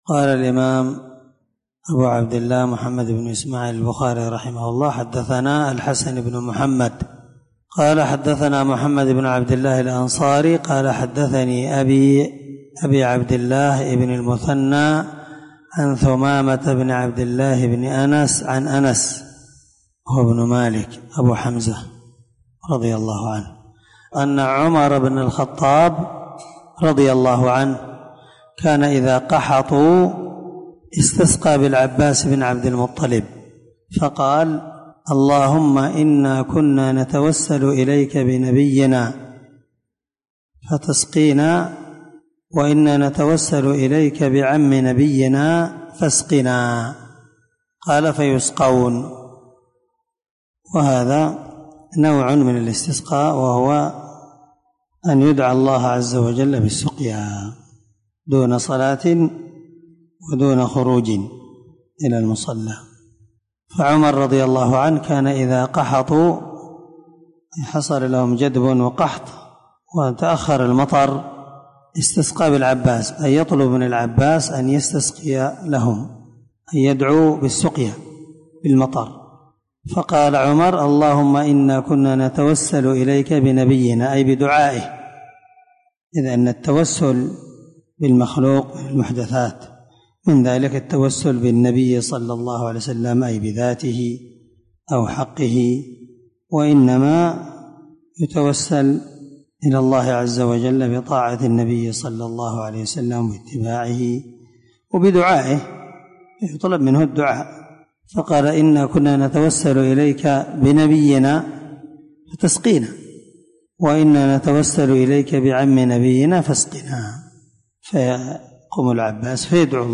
دار الحديث- المَحاوِلة- الصبيحة.